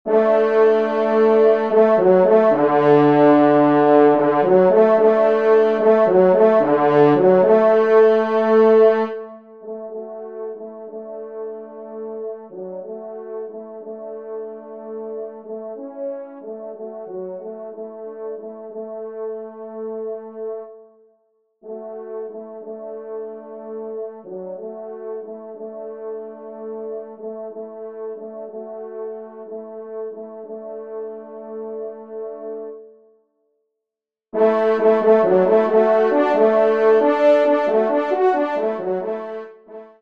Pupitre 3°Trompe